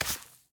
brushing_gravel2.ogg